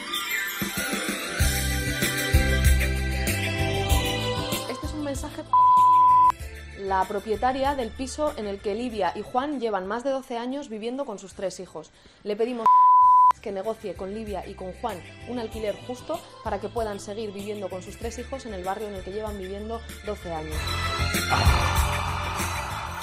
Declaraciones de Irene Montero